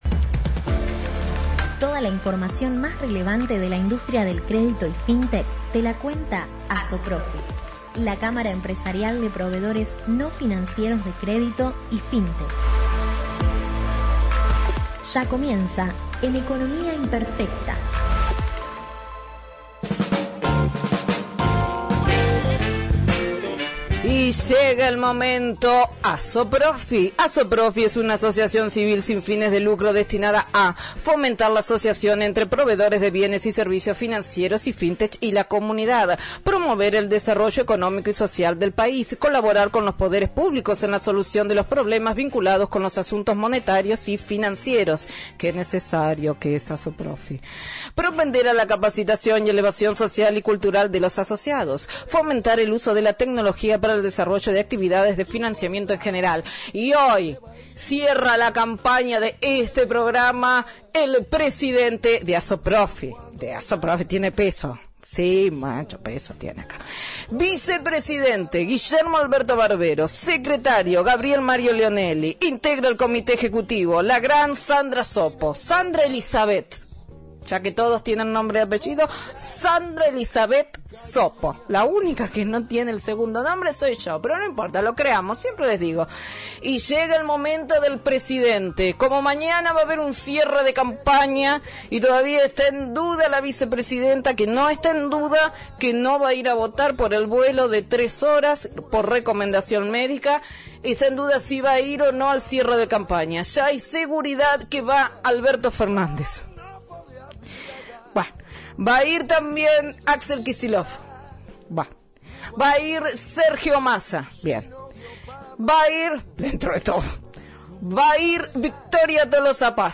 Para volver a escuchar la Columna Radial ingresando aquí: